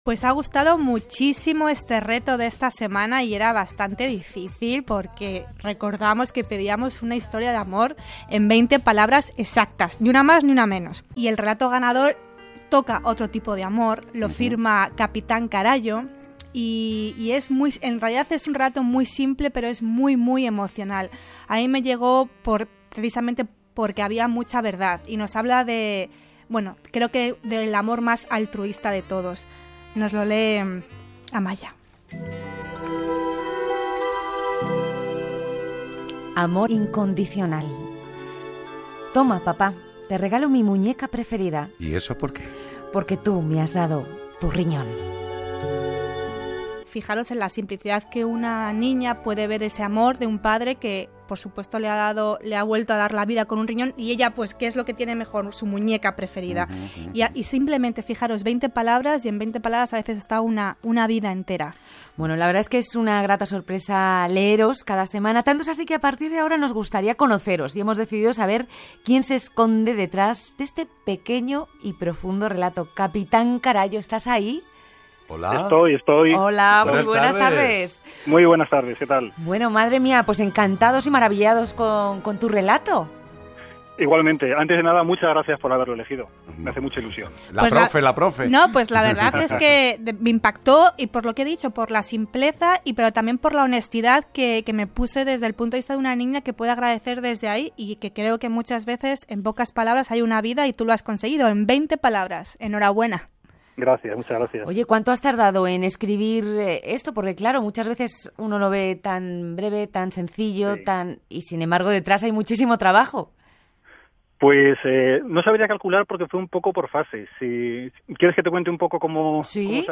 Resulta que lo han elegido como relato ganador del reto, así que aquí les dejo el corte donde pueden escucharlo y la breve entrevista que me hicieron después.